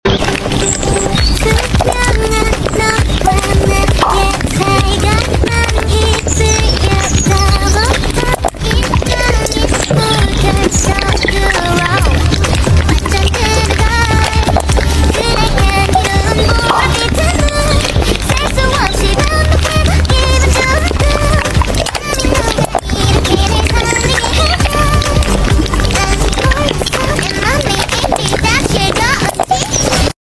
🧊🍝🍝🍯🧊 Asmr eating with closer sound effects free download